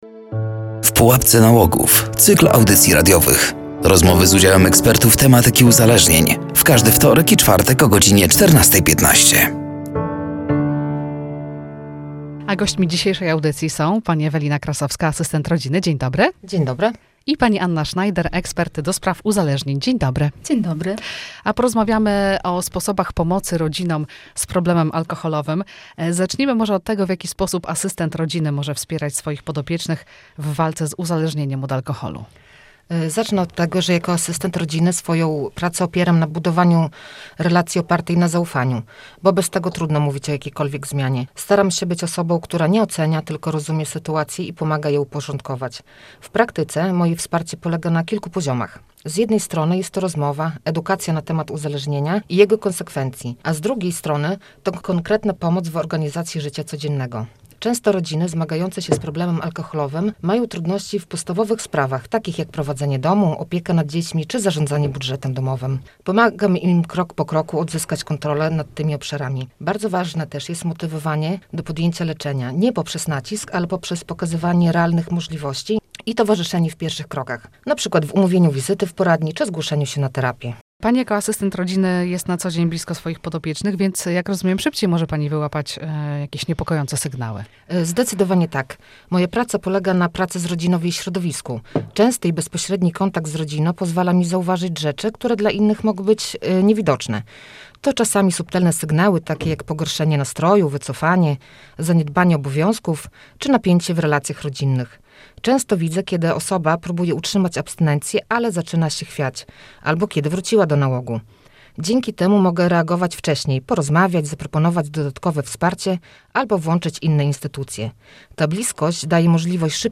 “W pułapce nałogów” cykl audycji radiowych poświęconych profilaktyce uzależnień wśród dzieci i młodzieży. Rozmowy z udziałem ekspertów tematyki uzależnień.